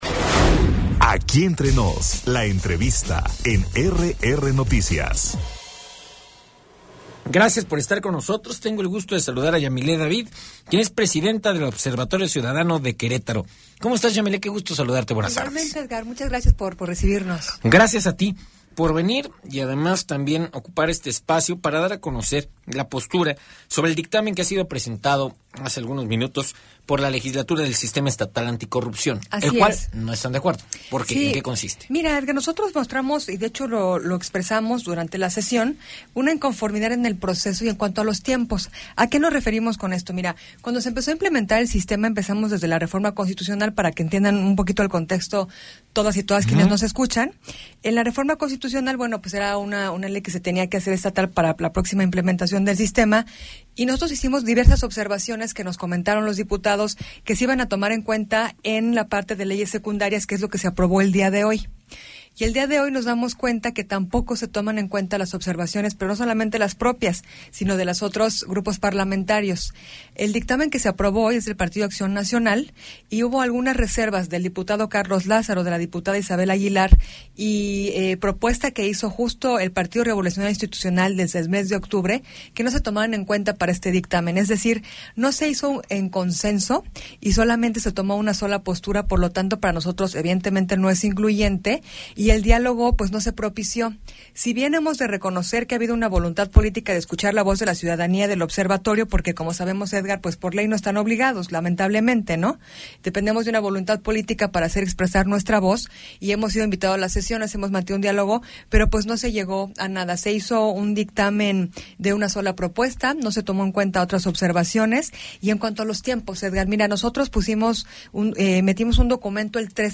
EntrevistasMultimediaPodcast